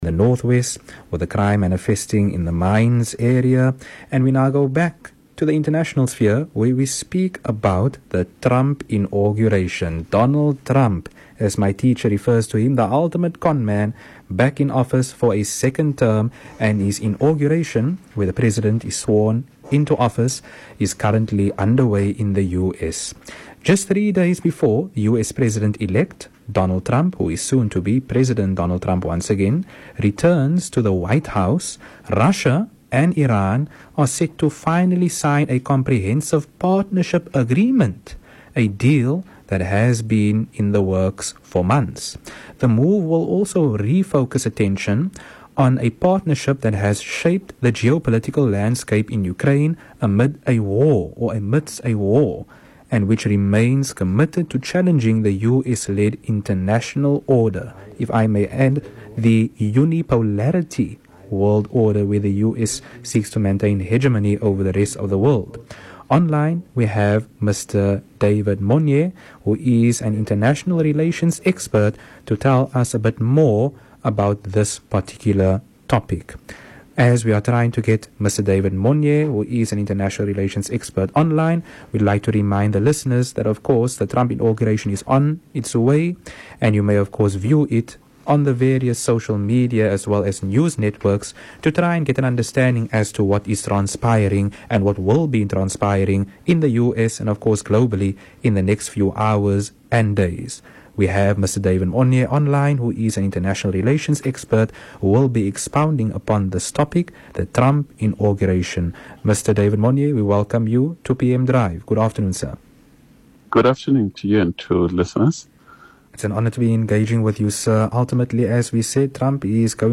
Speaking on VOC’s PM Drive show Drive earlier today